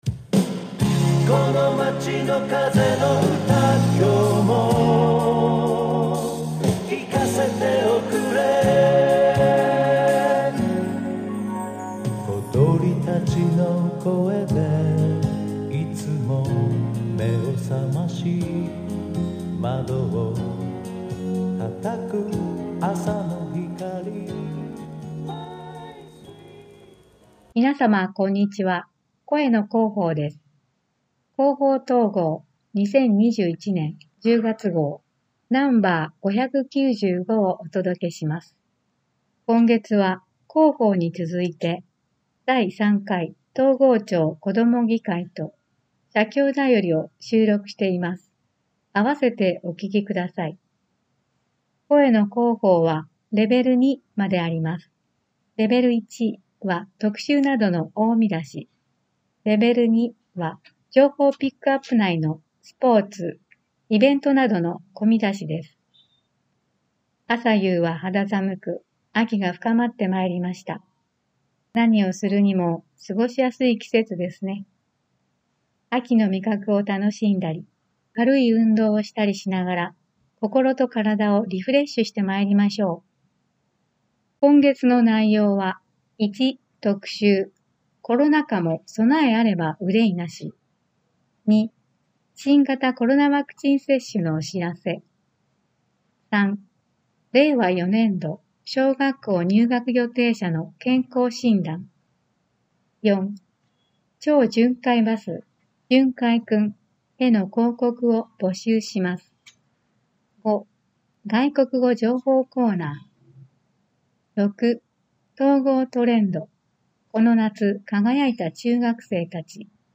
広報とうごう音訳版（2021年10月号）